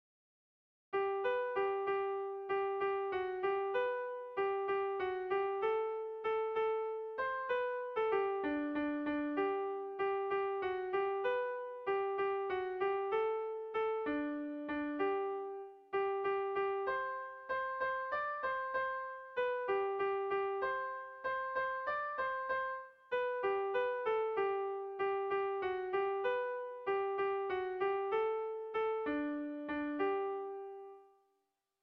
Air de bertsos - Voir fiche   Pour savoir plus sur cette section
Zortziko handia (hg) / Lau puntuko handia (ip)
A1A2BA2